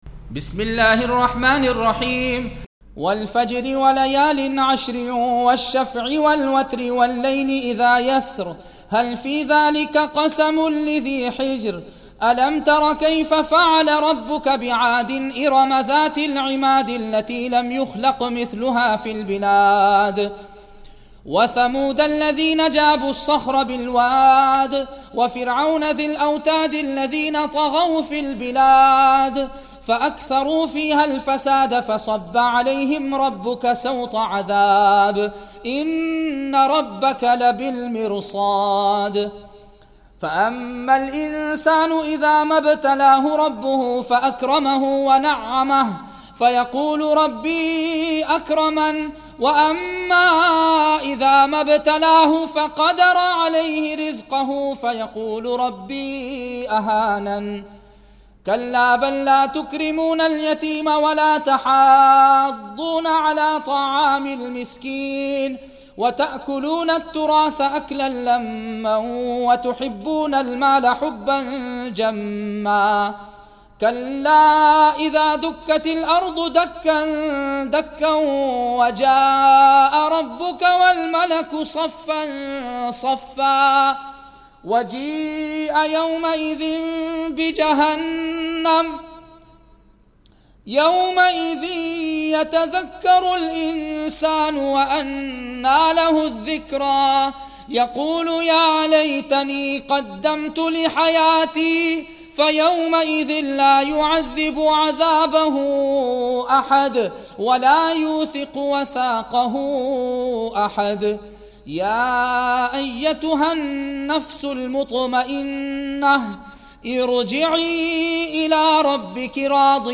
Recitation of the Noble Qur'aan